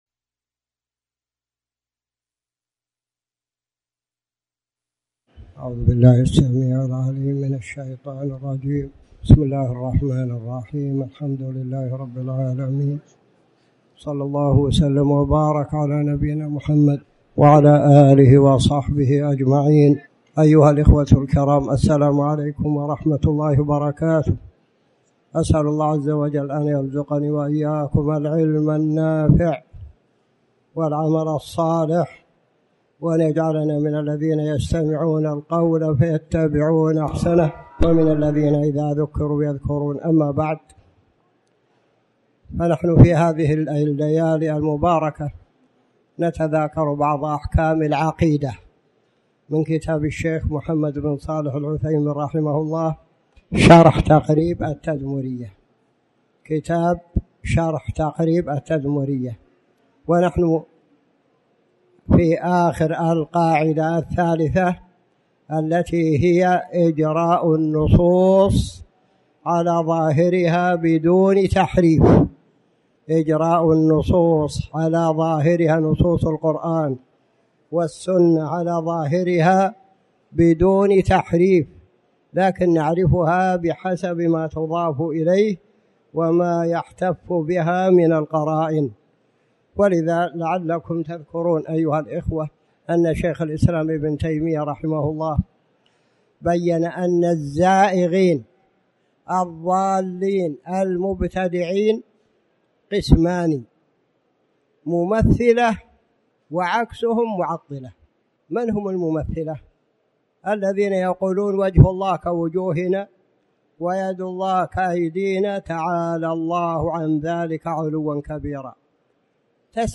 تاريخ النشر ٢٦ ذو القعدة ١٤٣٩ هـ المكان: المسجد الحرام الشيخ